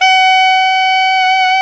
SAX ALTOMP0J.wav